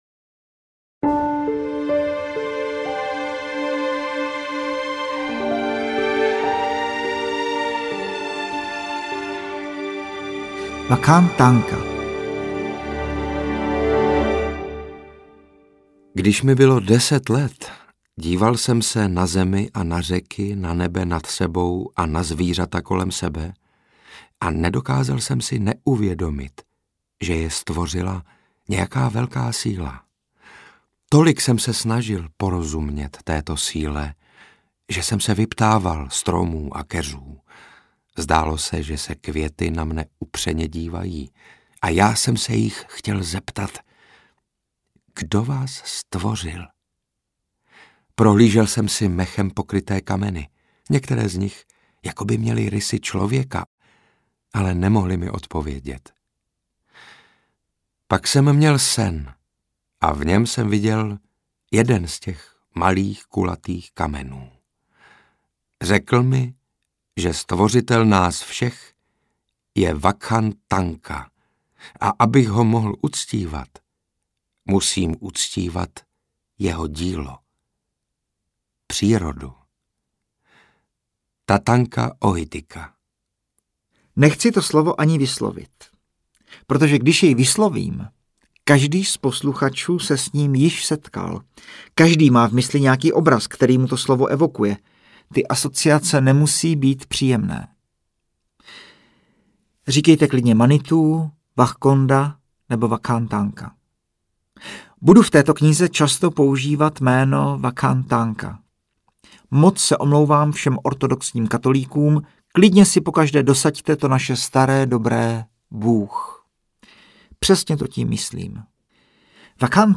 Wakan Tanka audiokniha
Ukázka z knihy